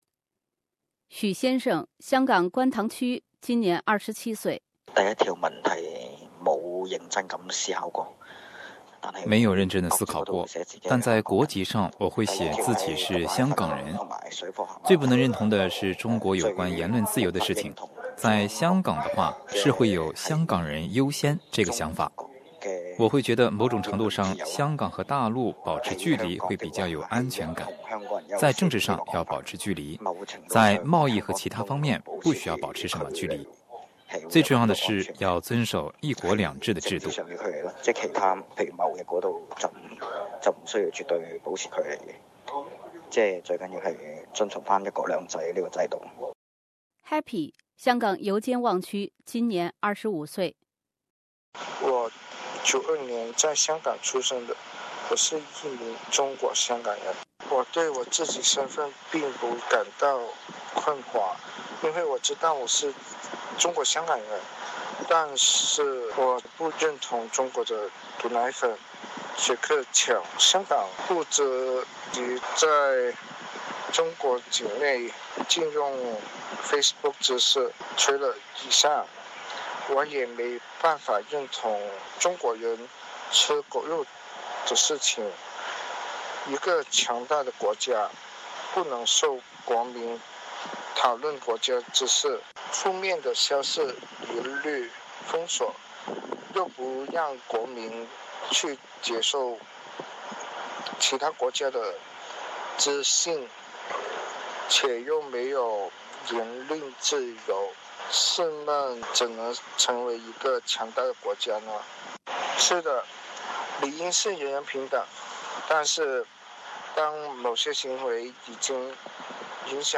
在7300个日子后，我们很想了解回归后的港人对于中国的认同感，尤其是年轻一代香港人对于中国的认同感。于是，这份年龄介于24岁到30岁之间，涵盖香港本地居民、新香港人、海外香港移民、在中国大陆工作香港人的 香港年轻人对中国认同感调查报告以一种音频杂志的形式诞生了。